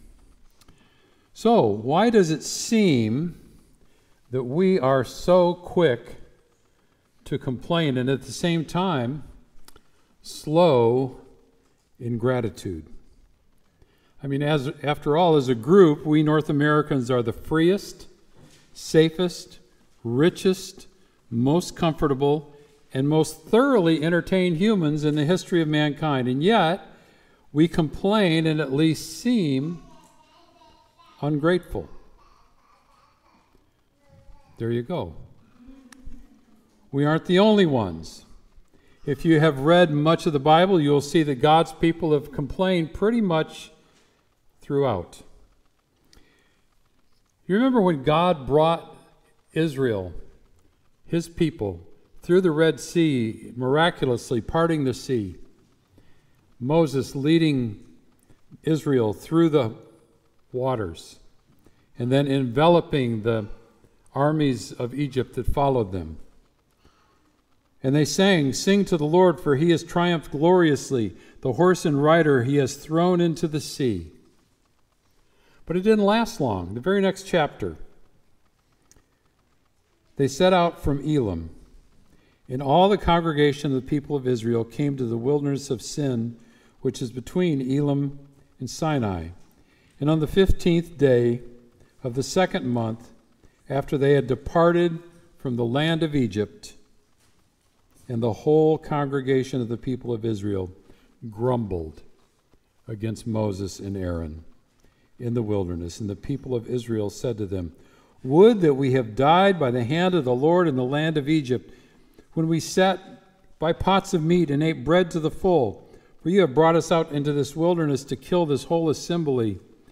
Sermon “Be Thankful”